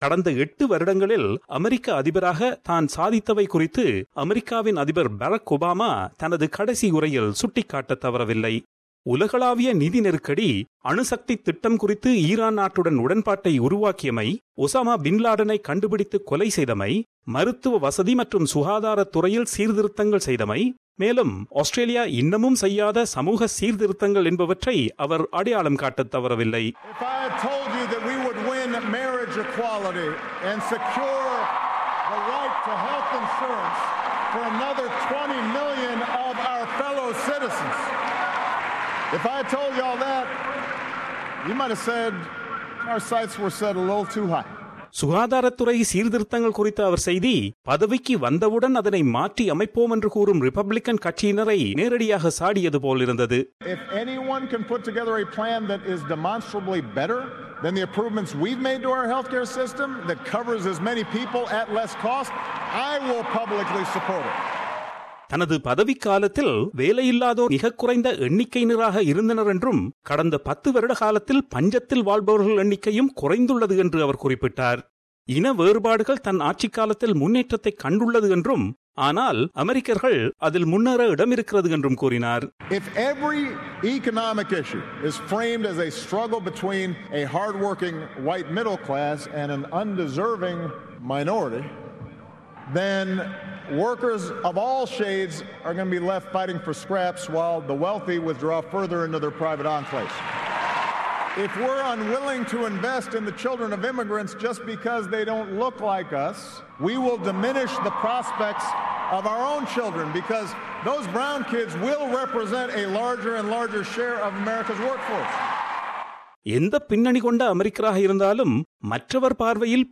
US President Barack Obama has delivered his farewell address, urging Americans to defend their democracy.During the address in Chicago, Mr Obama warned democracy is threatened whenever people take it for granted. He mentioned three main threats to America's democracy - economic inequality, racial divisions and the tendency for people to retreat into their own bubbles.